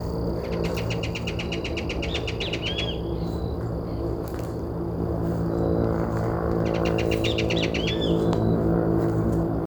Ringed Kingfisher (Megaceryle torquata)
Location or protected area: Concordia
Condition: Wild
Certainty: Photographed, Recorded vocal